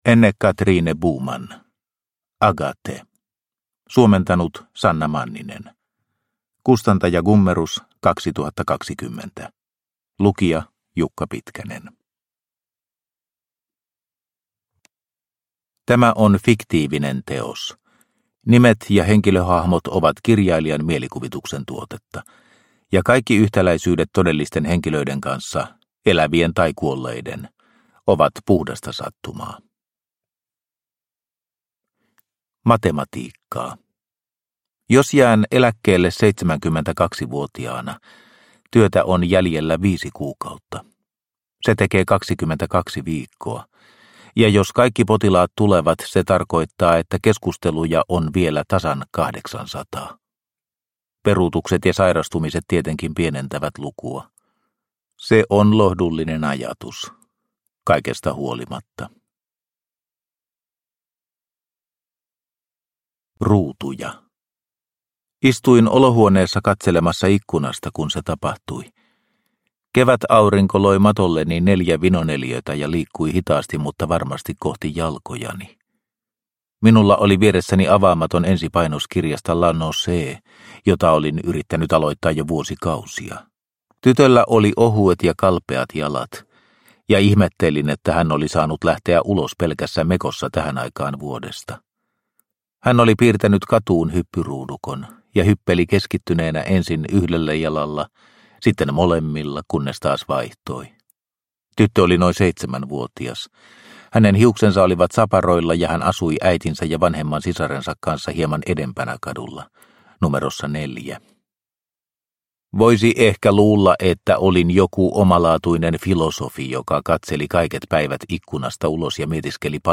Agathe – Ljudbok – Laddas ner